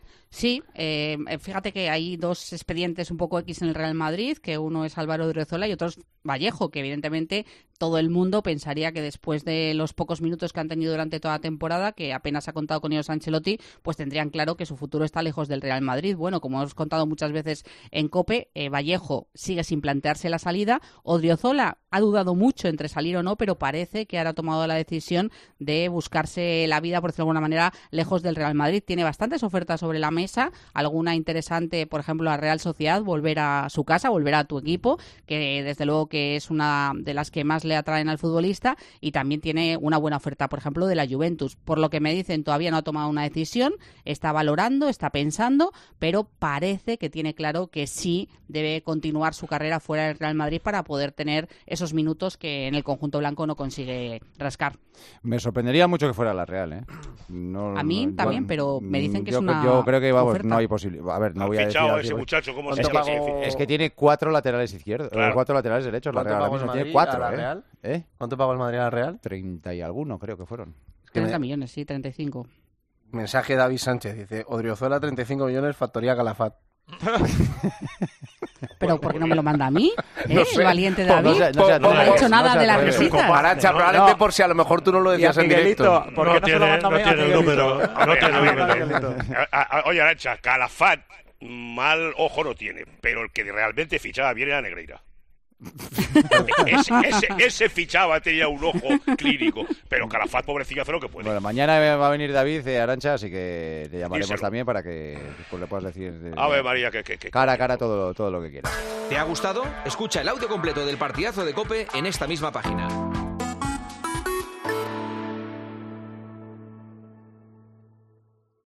¿Qué es la factoría Calafat? Los tertulianos de El Partidazo de COPE debaten sobre este término